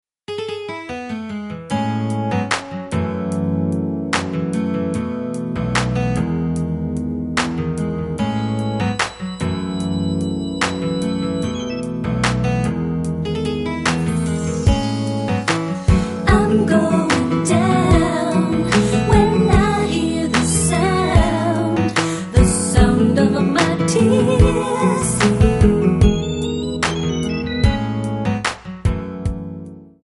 Db
MPEG 1 Layer 3 (Stereo)
Backing track Karaoke
Pop, Musical/Film/TV, 1990s